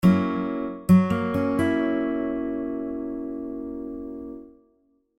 Now, let’s try F#7, does it sound like the example below?
F#7 Chord
F7-Chord.mp3